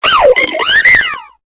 Cri de Pashmilla dans Pokémon Épée et Bouclier.